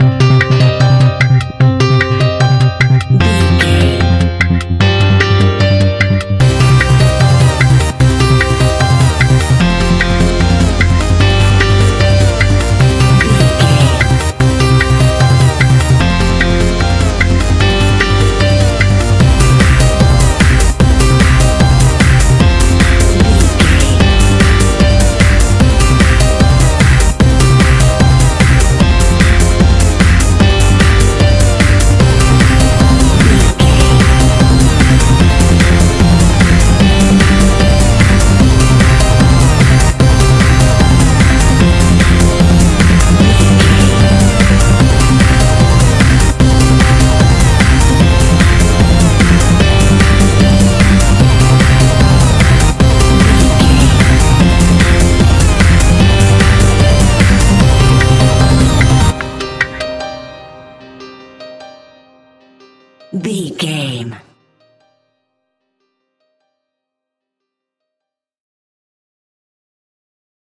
Aeolian/Minor
Fast
energetic
futuristic
hypnotic
drum machine
synthesiser
piano
acid house
uptempo
synth leads
synth bass